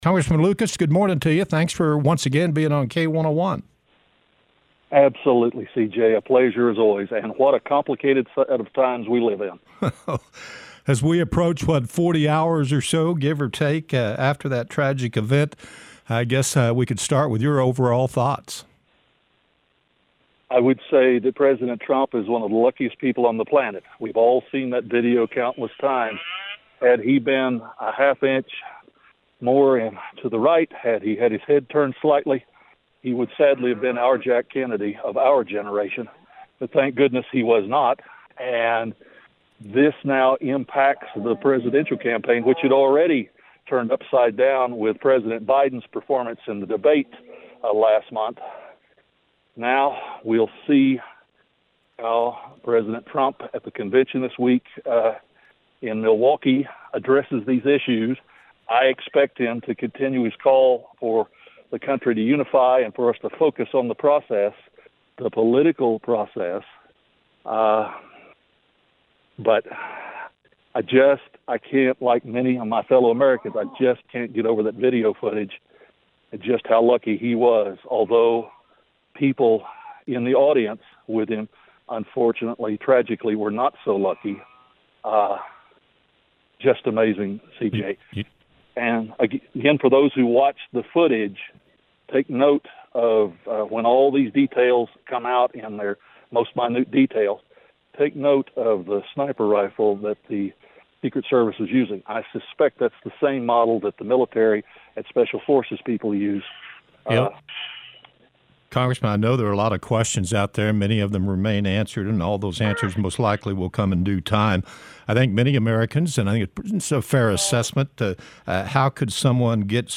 Congressman Frank Lucas joined the K-101 Morning Show Monday to update the latest on what is known about the assassination attempt on former President Donald Trump, Congressional inquiries into the Secret Service’s ability to protect the current and former Presidents, and his thoughts on the current political climate.